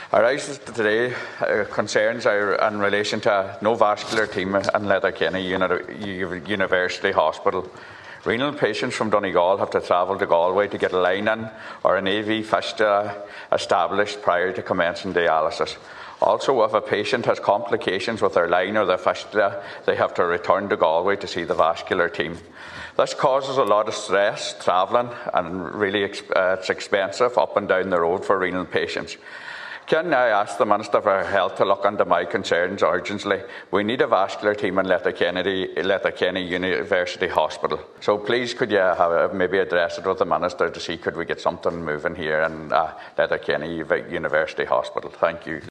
The Seanad chamber has heard concerns over the absence of a vascular team at Letterkenny University Hospital.
Senator Boyle asked that the issue be discussed with the Health Minister: